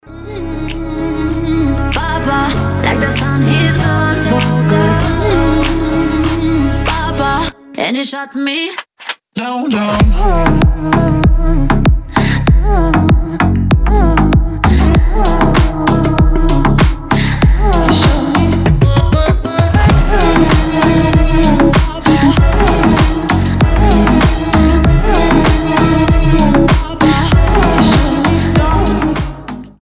• Качество: 320, Stereo
заводные
dance